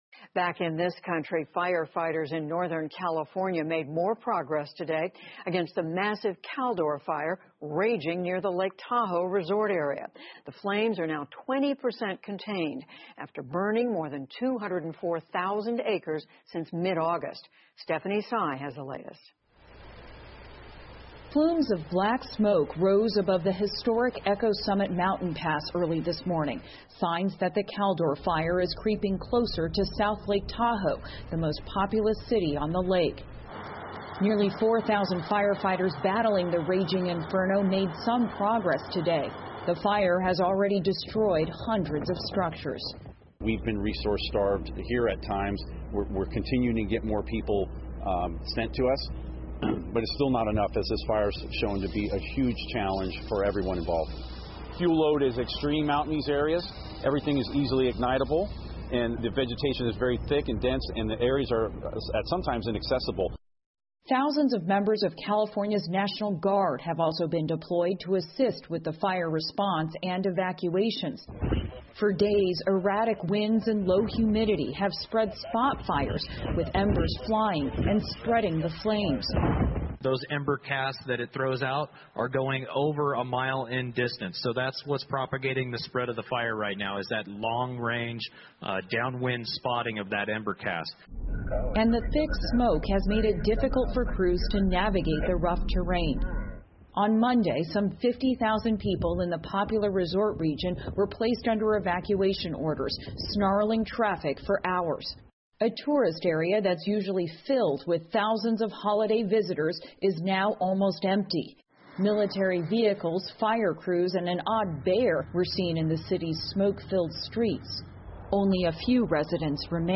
PBS高端访谈:美国"卡尔多山火"肆虐,已烧毁数百座建筑 听力文件下载—在线英语听力室